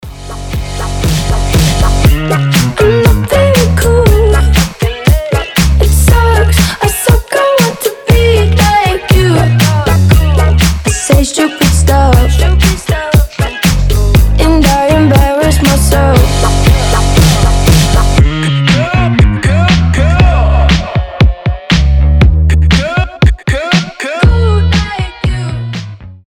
• Качество: 320, Stereo
гитара
громкие
забавные
озорные
Фанк